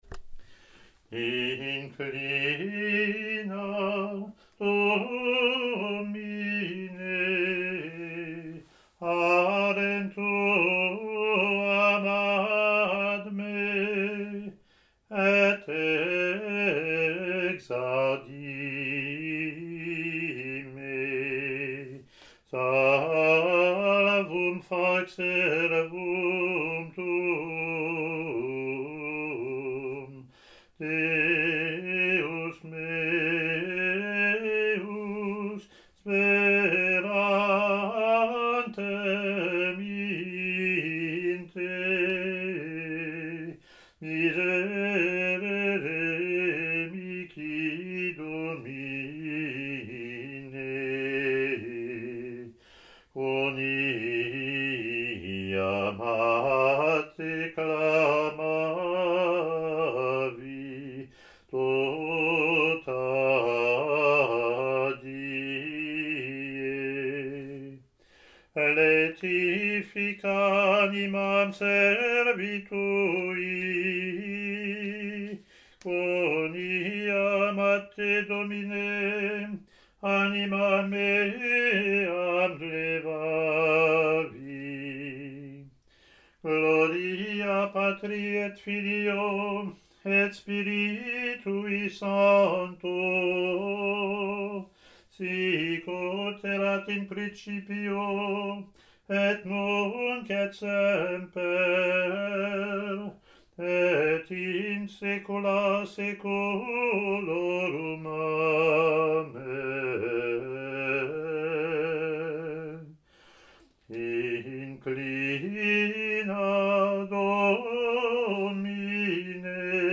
Introit in latin.)